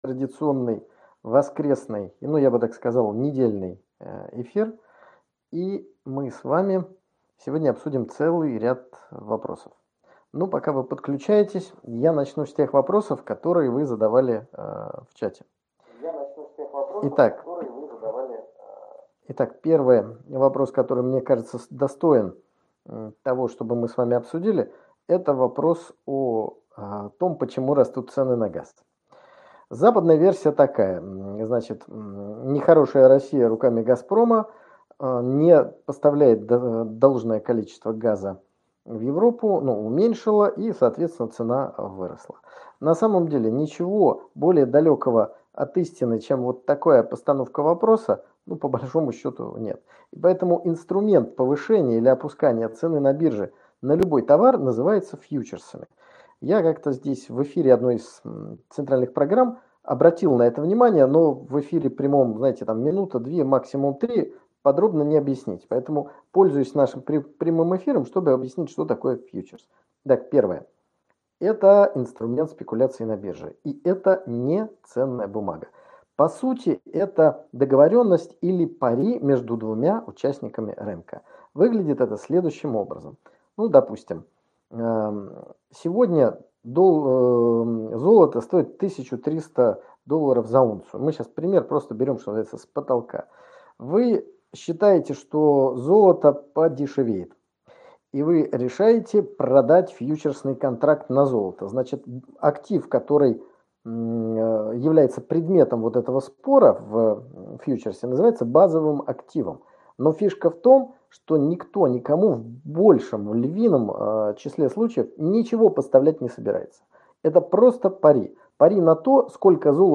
В очередном воскресном прямом эфире было много интересных вопросов.